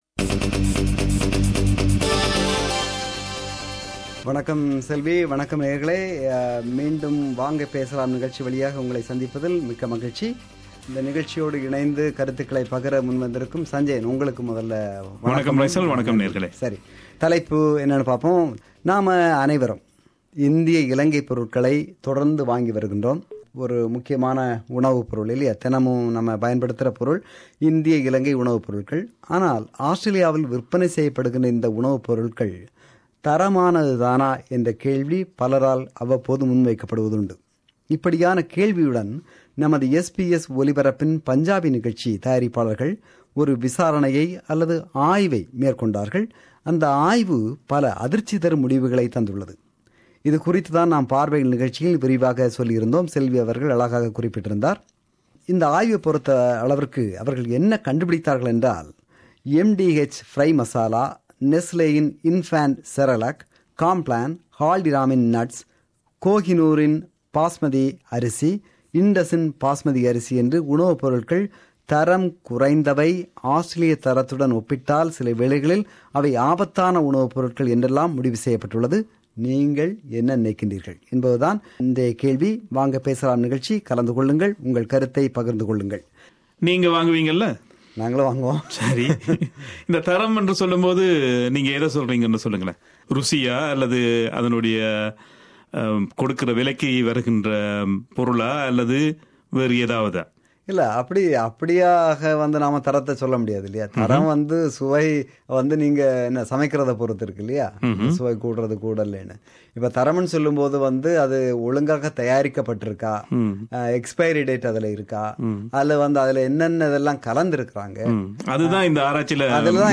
This is the compilation of listeners & comments in our Vanga Pesalam program on 28 October.